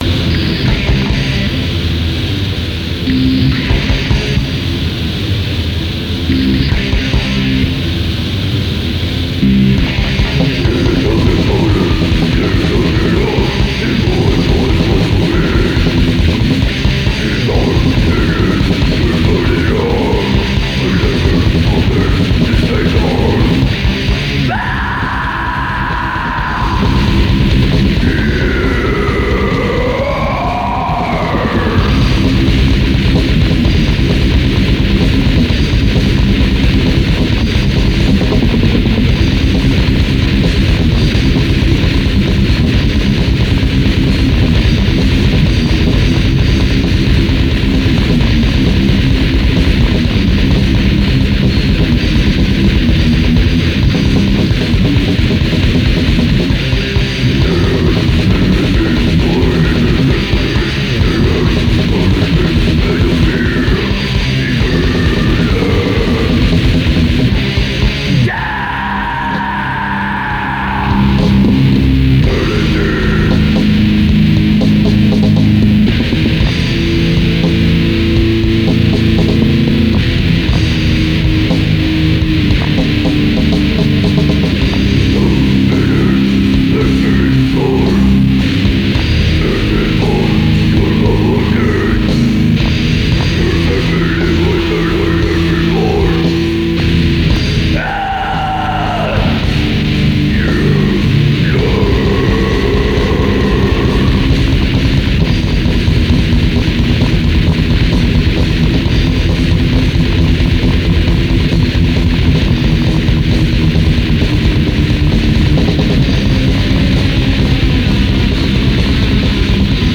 Das erste und einzige Demotape der Band